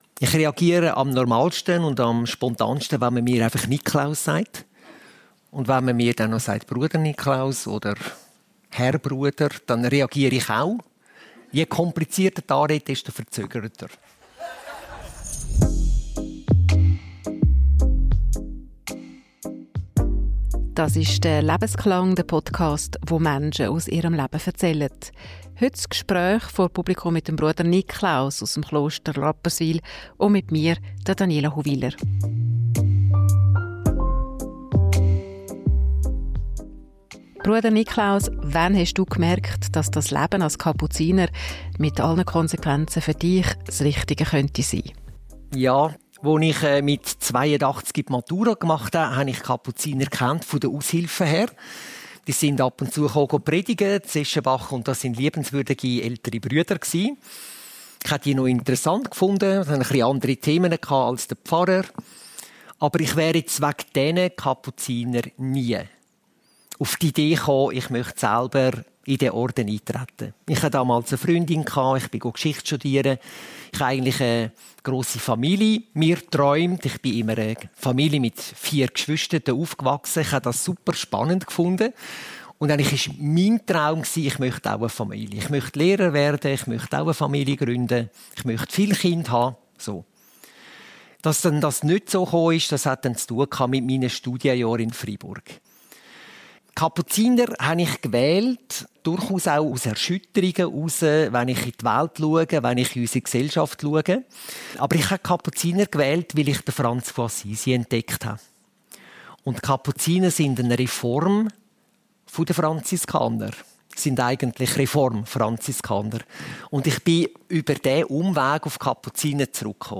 Ein persönliches Gespräch über Glauben, Verantwortung und Menschlichkeit.